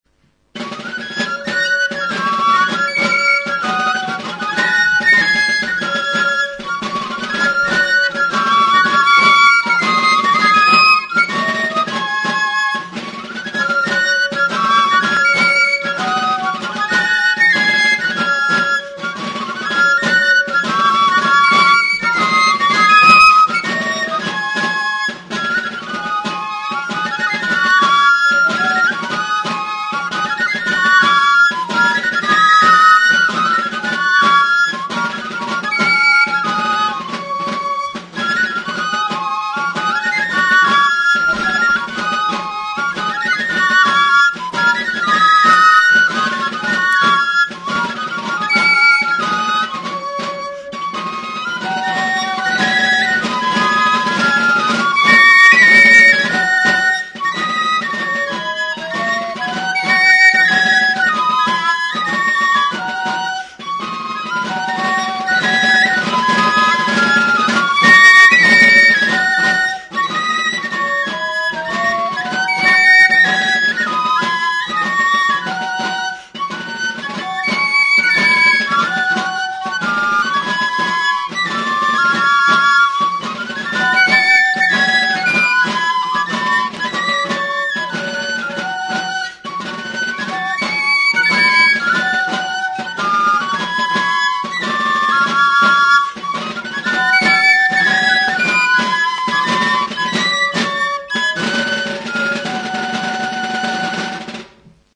Aerophones -> Flutes -> Fipple flutes (one-handed)
ZORTZIKO. Iruñeko Udal Txistu Banda. HM Udazkeneko Kontzertua.
TXISTUA